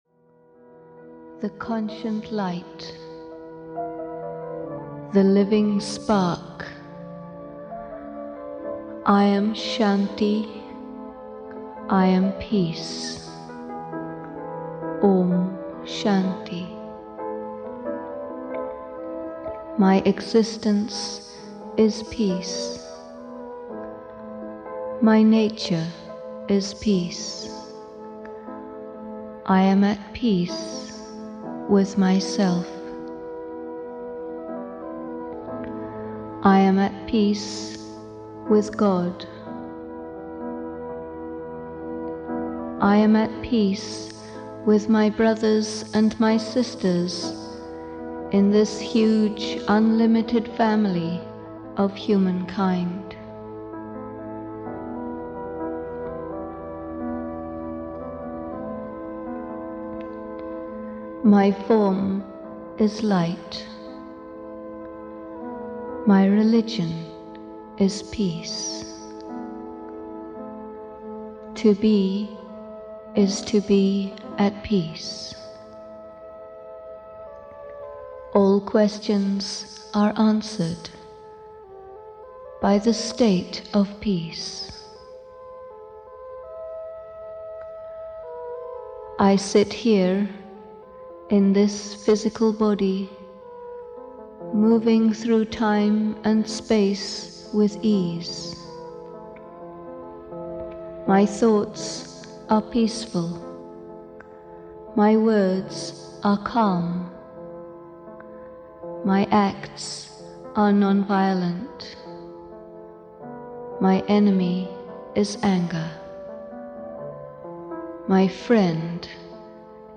Inner Treasures (EN) audiokniha
Ukázka z knihy